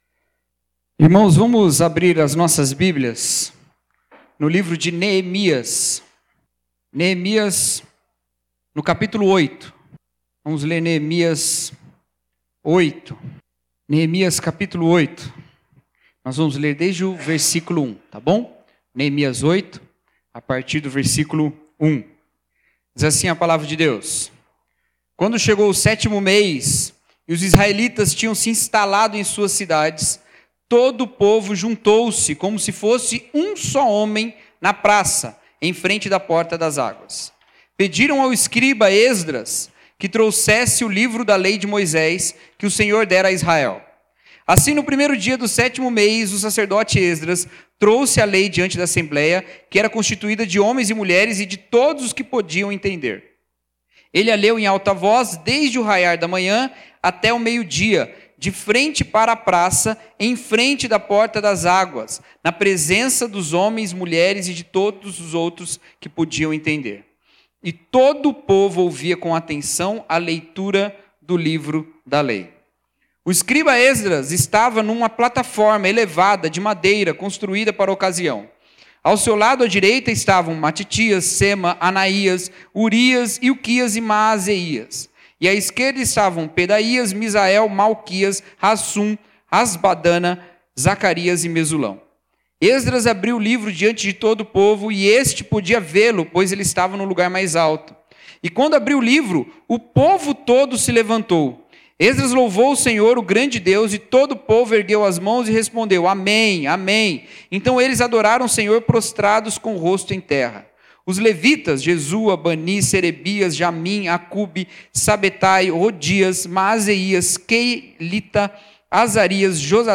Pregador